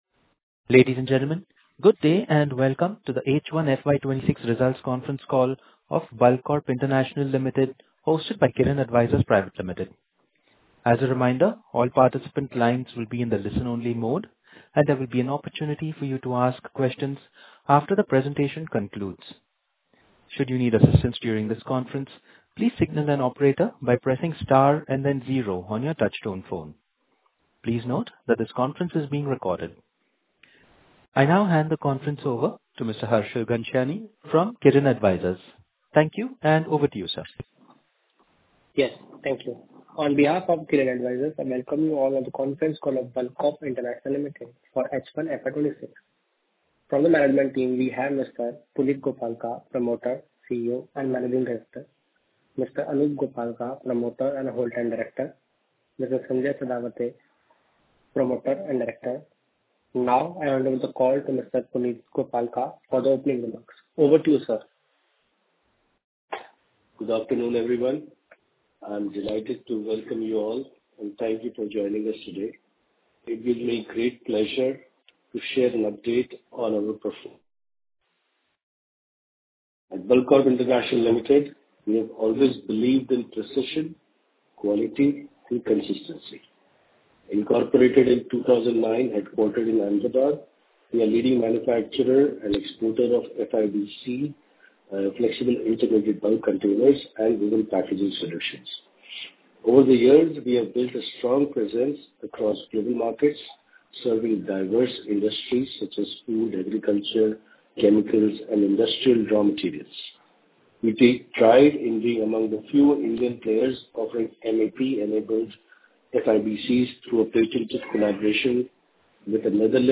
fy26-results-conference-call-recording.mp3